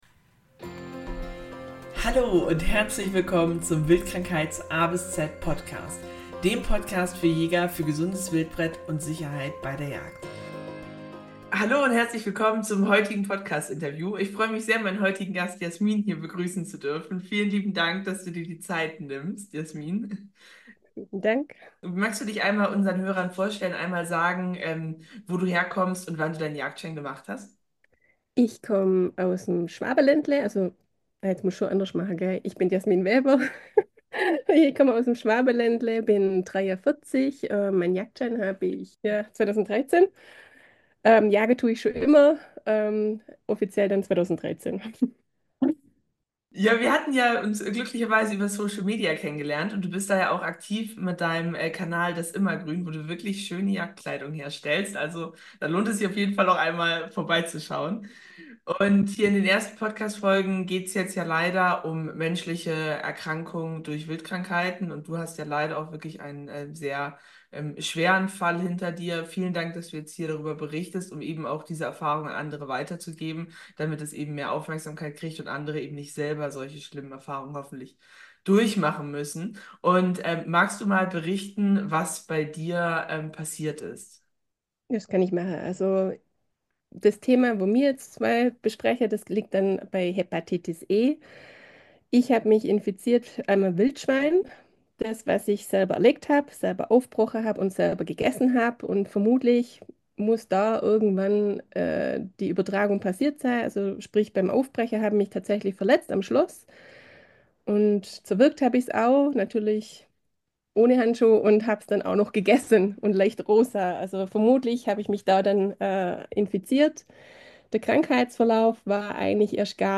Beschreibung vor 1 Jahr Folge 1: Erfahrungsbericht Hepatitis E - eine unterschätzte Gefahr für Jäger In dieser Folge spreche ich mit einer Jägerin, die durch ein Wildschwein mit Hepatitis E infiziert wurde – eine Krankheit, die sie schwer getroffen hat. Sie teilt ihre persönlichen Erfahrungen, wie es zu der Infektion kam, wie es ihr damit erging und was sie anderen Jägern rät, um sich zu schützen.